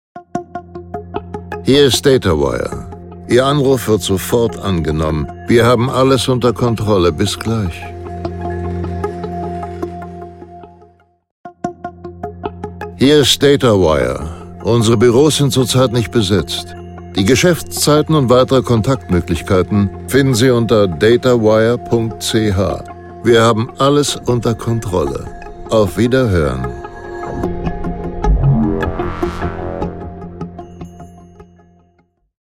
Datawire Ansage
Datawire_Ansage-vor-Melden-AB_Demo.mp3